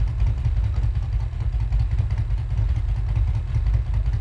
rr3-assets/files/.depot/audio/Vehicles/v8_08/v8_08_idle.wav
v8_08_idle.wav